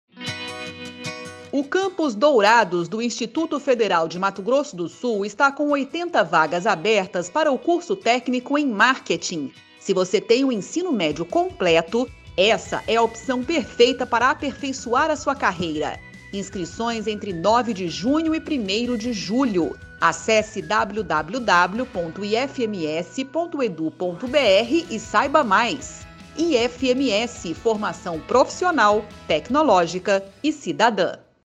Áudio enviado às rádios para divulgação institucional do IFMS